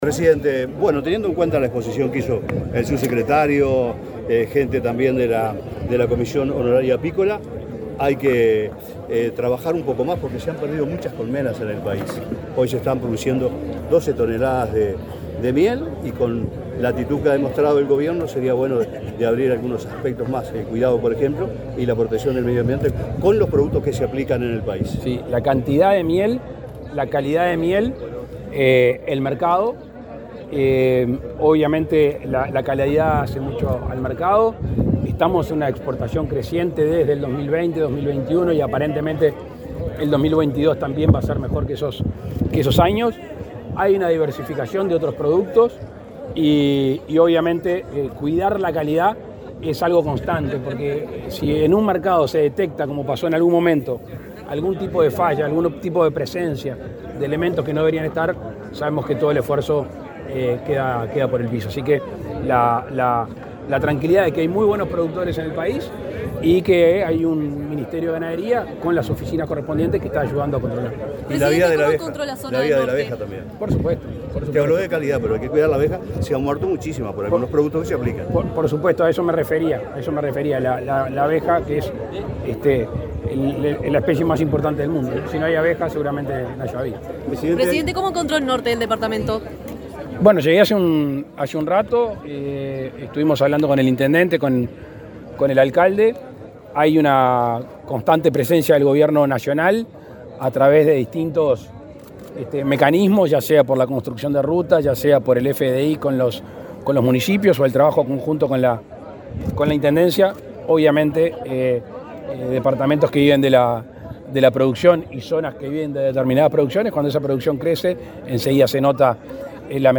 Declaraciones del presidente Lacalle Pou a la prensa
El presidente Luis Lacalle Pou asistió a la inauguración de la zafra apícola en el departamento de Lavalleja y, luego, dialogó con la prensa.